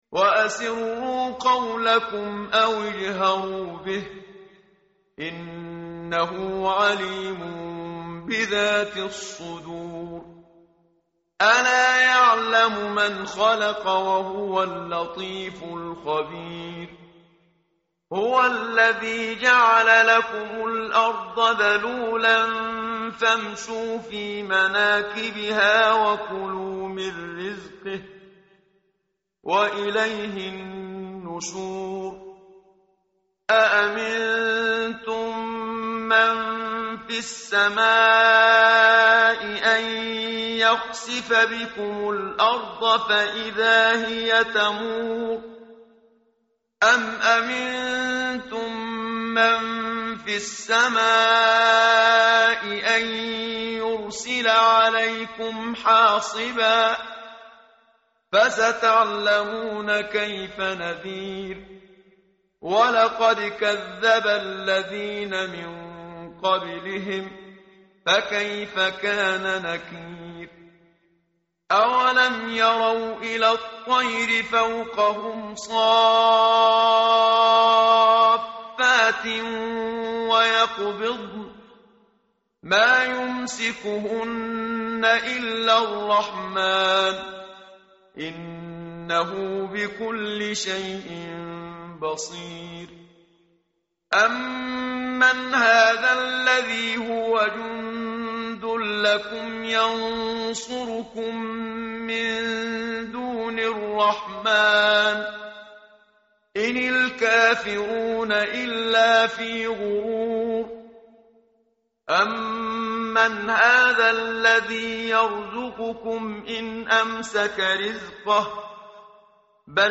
متن قرآن همراه باتلاوت قرآن و ترجمه
tartil_menshavi_page_563.mp3